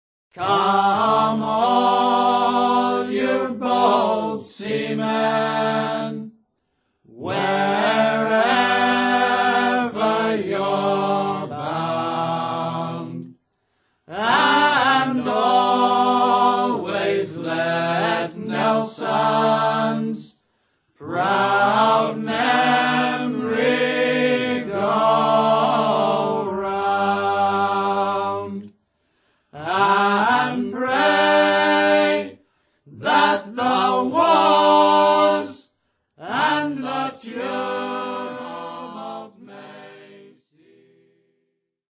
Songs of the North Atlantic Sailing Packets